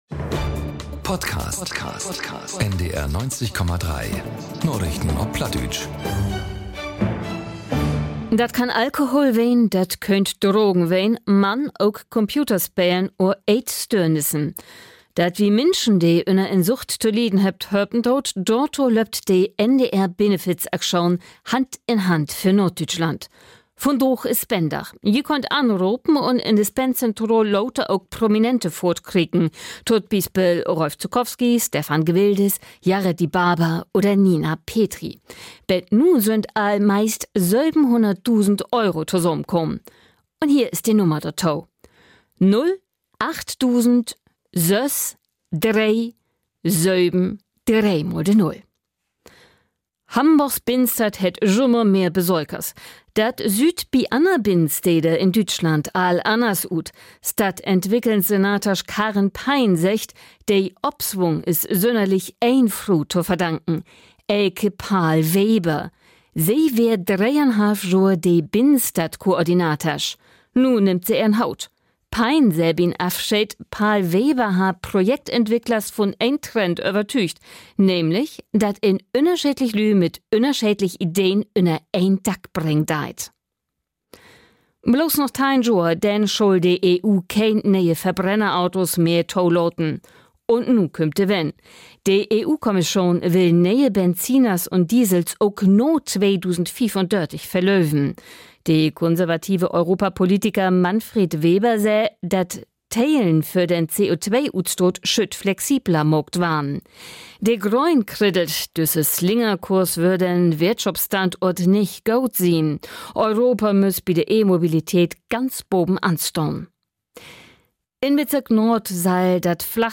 aktuellen Nachrichten auf Plattdeutsch.